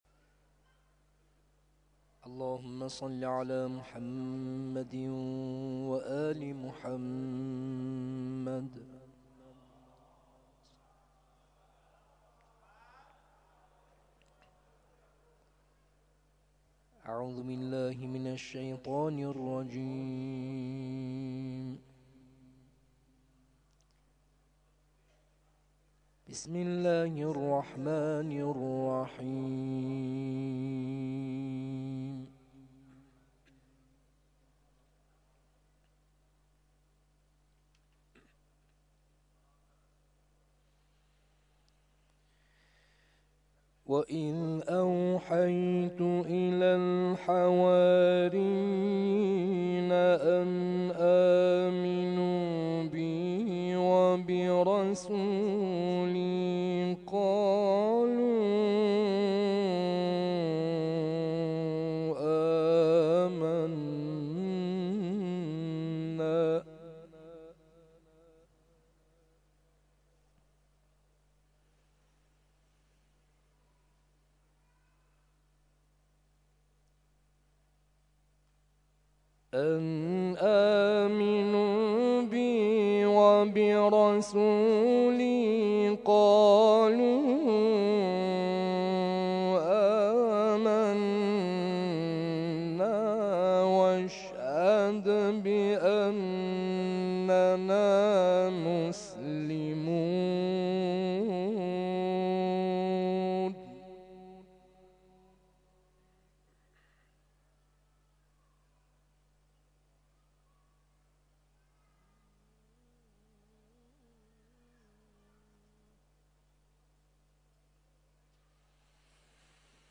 تلاوت مغرب